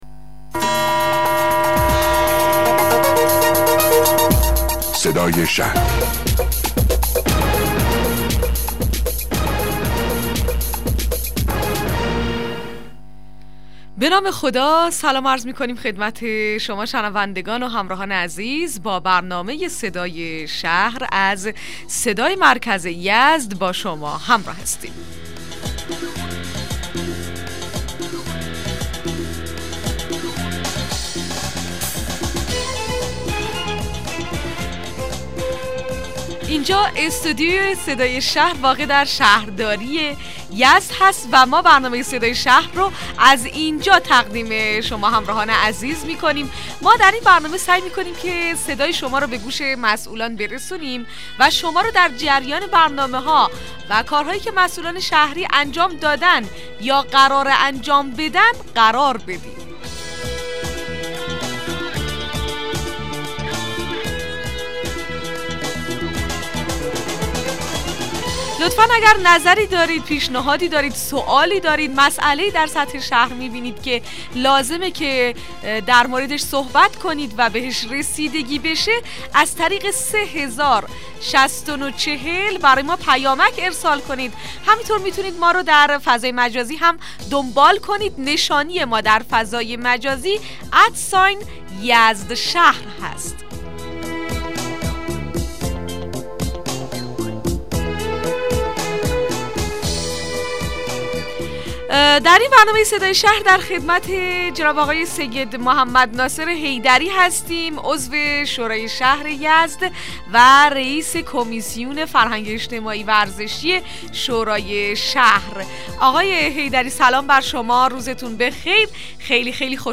مصاحبه رادیویی برنامه صدای شهر با سید محمد ناصر حیدری رییس کمیسیون فرهنگی شورای اسلامی شهر یزد